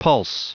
Prononciation du mot pulse en anglais (fichier audio)
Prononciation du mot : pulse